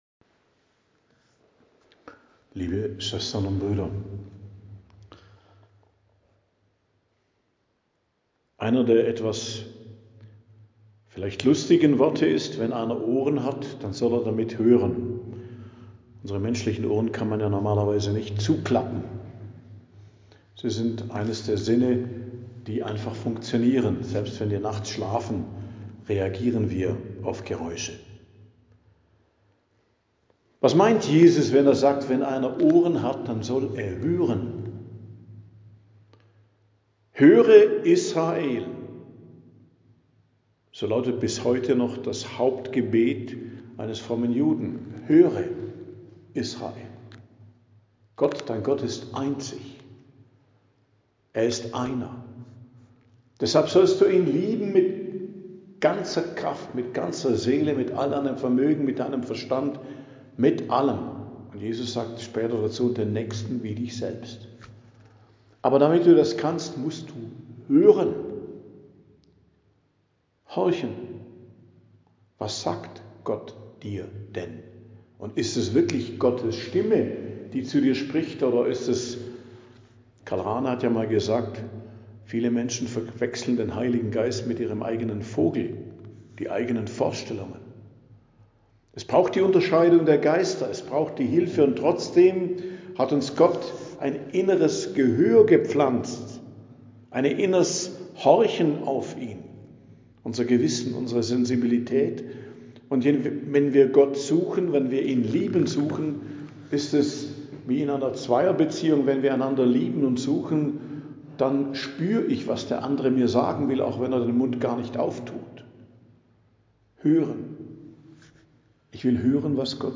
Predigt am Donnerstag der 3. Woche i.J., 29.01.2026